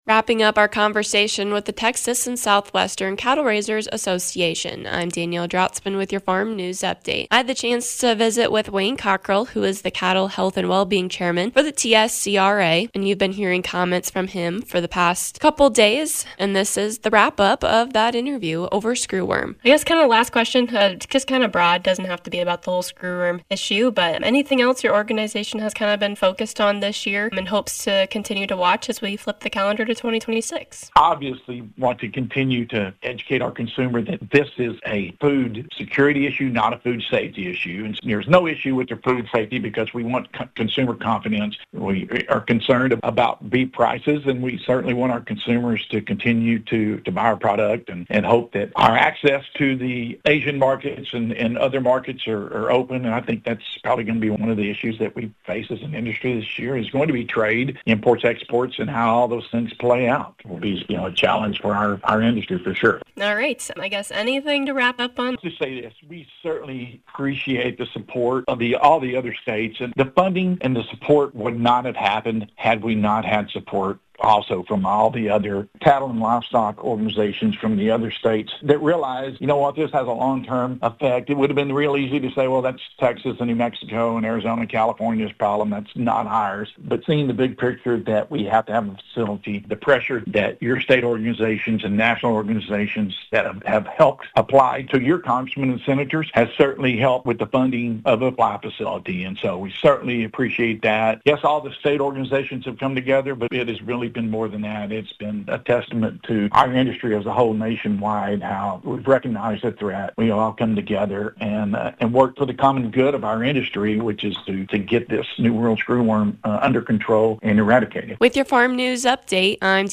More from the interview